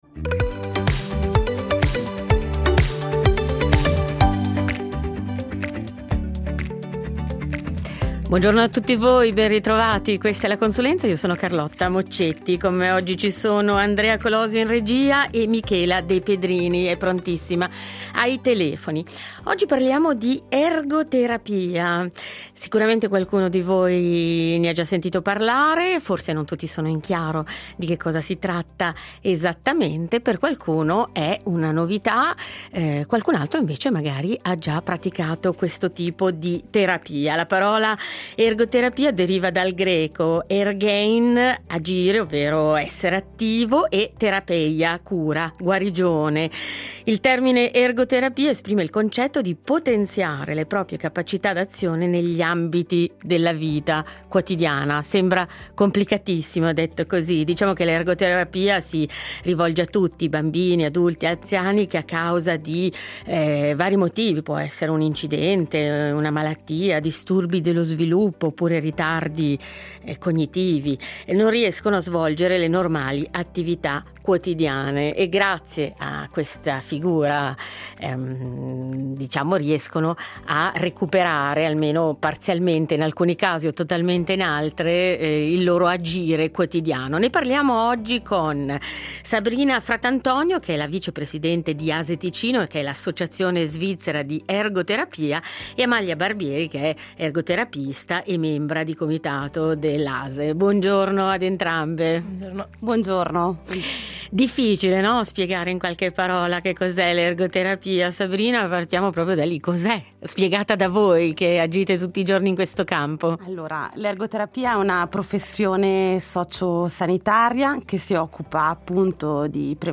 ergoterapista